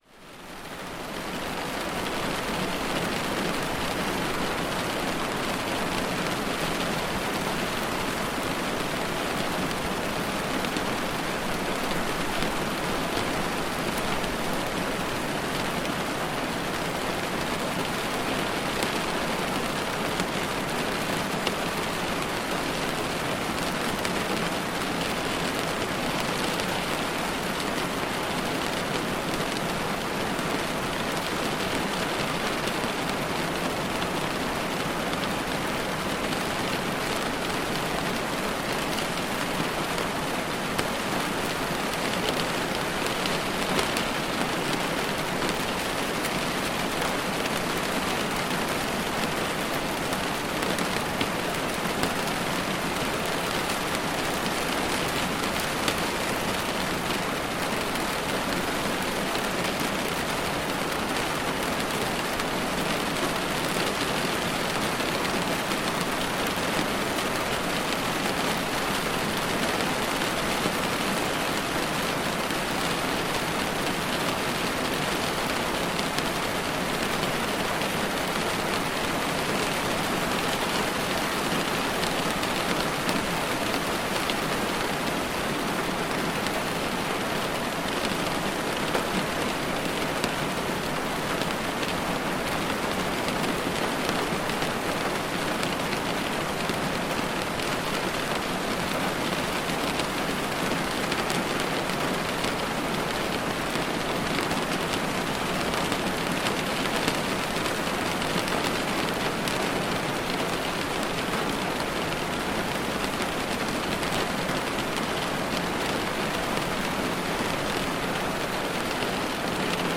Tempête de pluie en bruit brun pour clarifier les pensées et tomber dans le sommeil